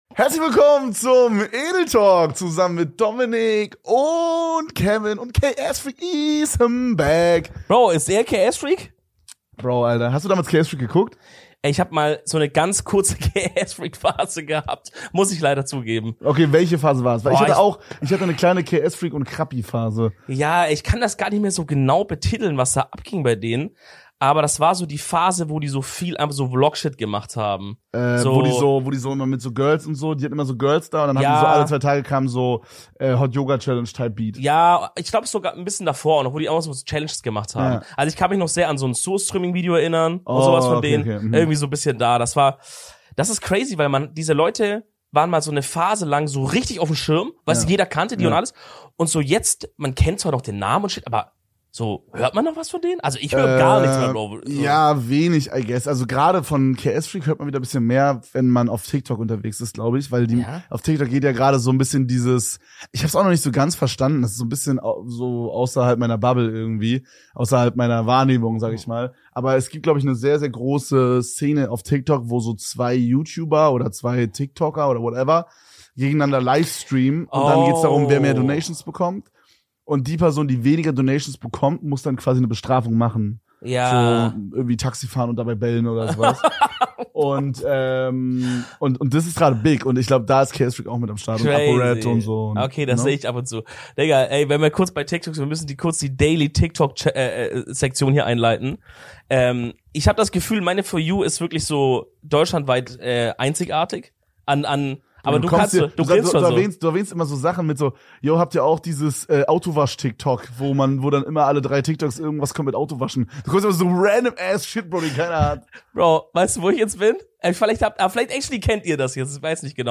Während wir bereits live auf der Edeltour unterwegs sind haben wir hier noch für euch eine kleine Studiofolge parat.